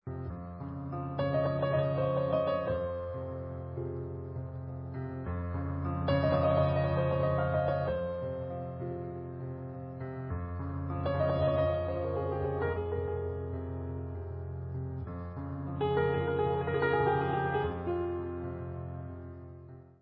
Klasika